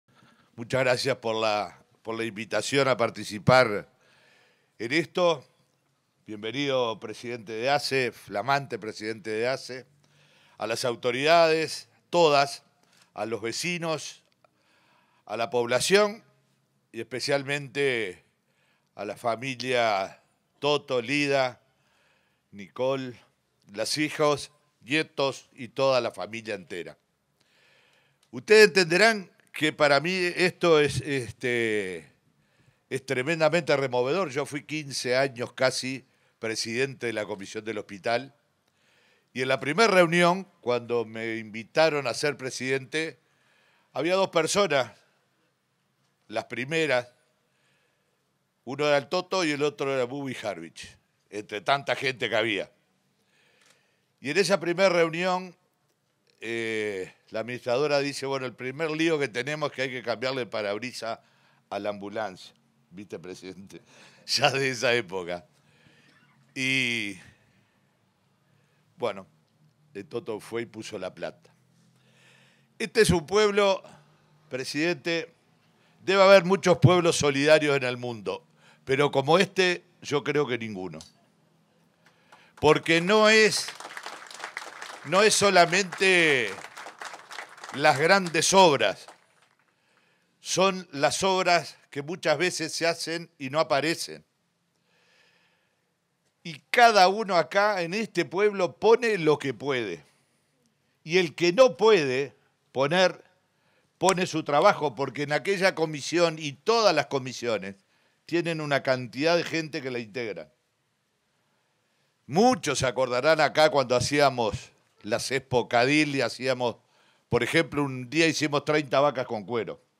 Palabras del intendente de Río Negro, Omar Lafluf
Palabras del intendente de Río Negro, Omar Lafluf 17/06/2024 Compartir Facebook X Copiar enlace WhatsApp LinkedIn En la ceremonia de inauguración de obras del Servicio de Internación Pediátrica y equipamiento para la Maternidad del Hospital de Young, se expresó el intendente de Río Negro, Omar Lafluf.